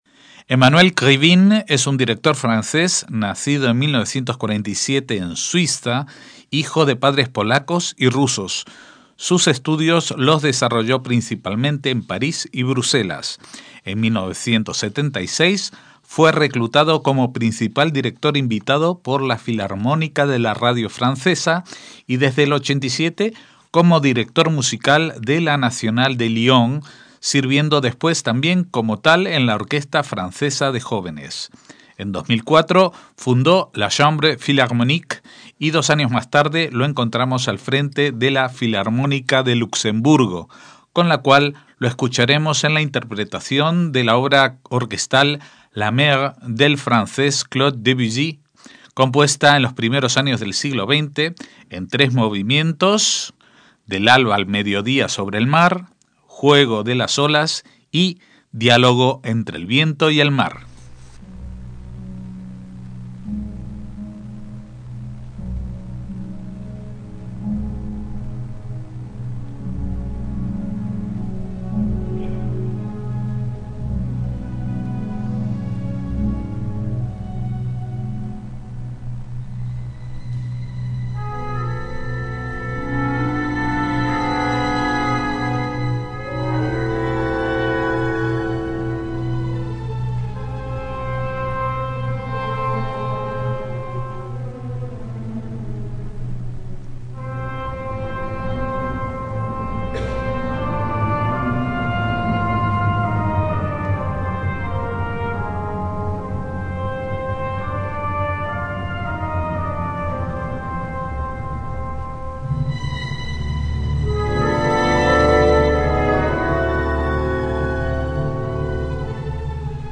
Krivine dirige "La Mer" de Debussy
MÚSICA CLÁSICA - El director de orquesta francés Emmanuel Krivine nació en Grenoble en 1947, hijo de madre polaca y padre ruso.